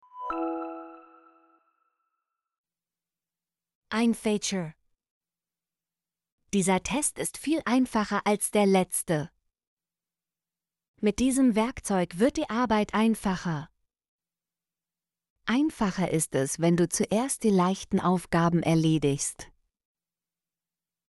einfacher - Example Sentences & Pronunciation, German Frequency List